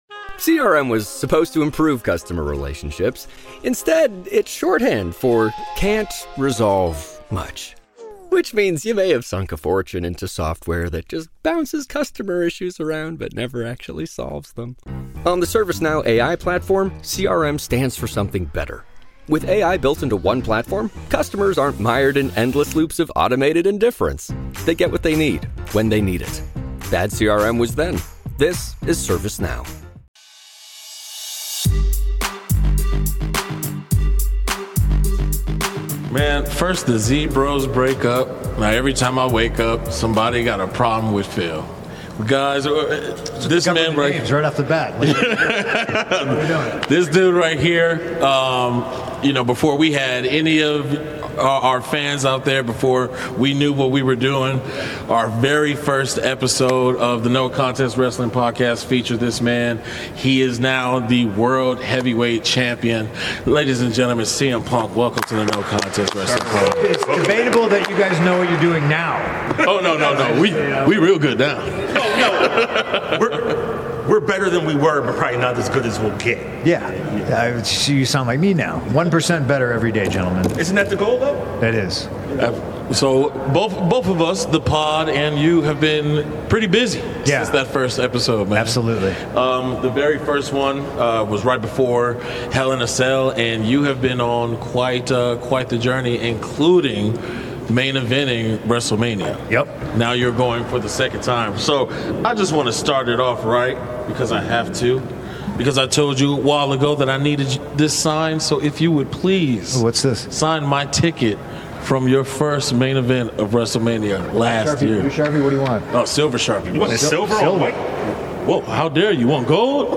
Exclusive Interview with CM Punk